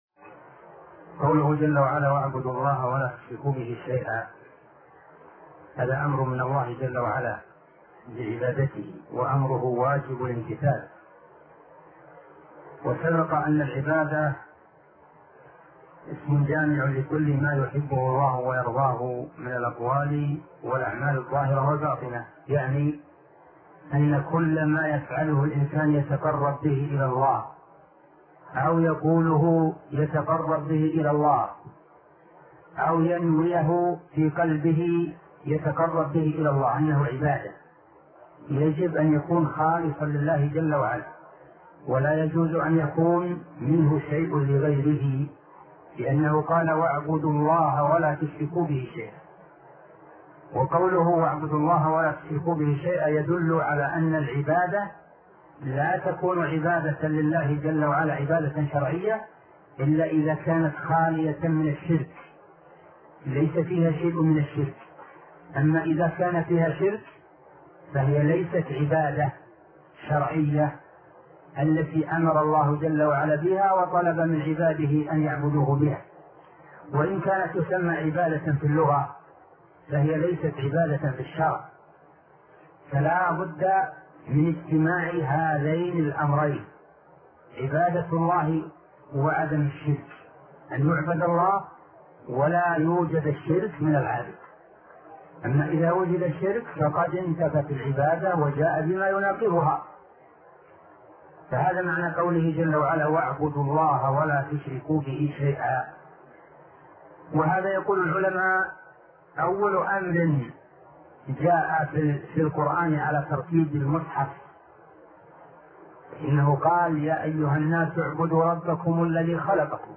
عنوان المادة الدرس ( 6) شرح فتح المجيد شرح كتاب التوحيد تاريخ التحميل الجمعة 16 ديسمبر 2022 مـ حجم المادة 25.46 ميجا بايت عدد الزيارات 281 زيارة عدد مرات الحفظ 119 مرة إستماع المادة حفظ المادة اضف تعليقك أرسل لصديق